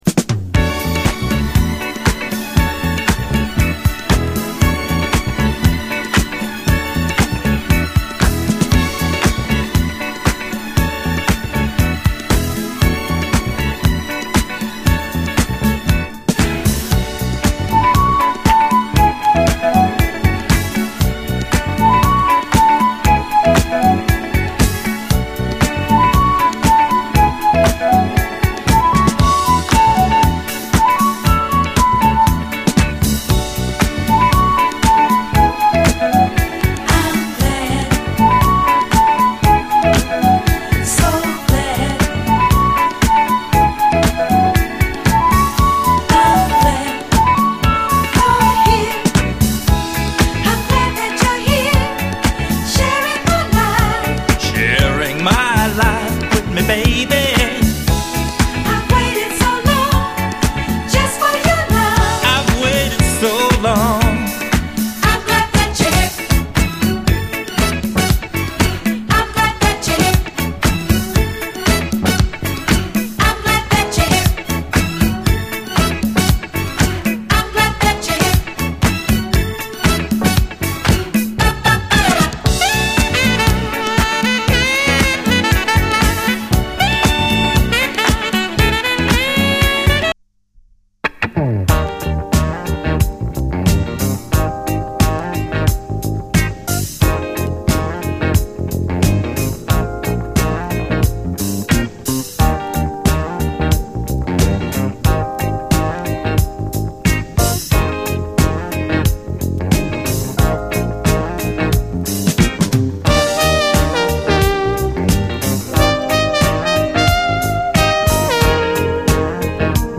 こちらもスペイシーでメロウな世界観に酔いしれる、内容最高のレアグルーヴ〜ジャズ・ファンク名盤！
銀河間を交信できそうなくらいに美しくスペイシーなLOFT的トラック！
泣ける最高のモダン・ソウル